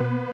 Freq-lead07.ogg